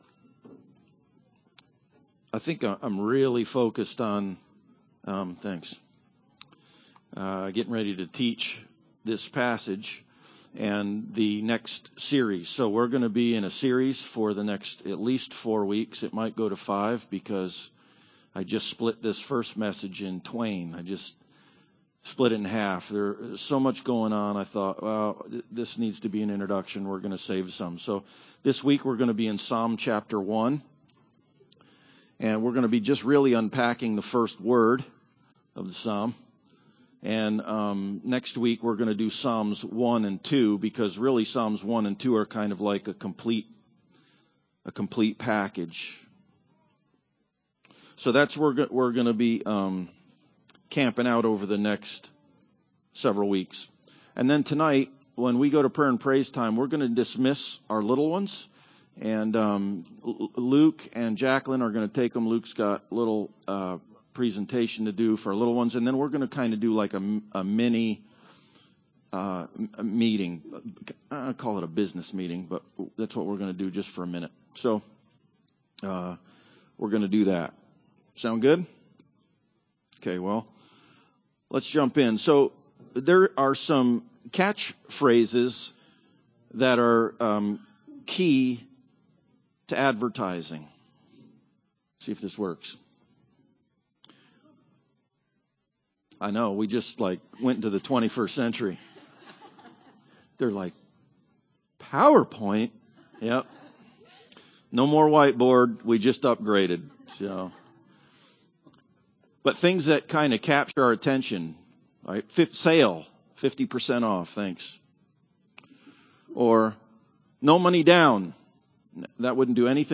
Meditation Service Type: Sunday Service Preacher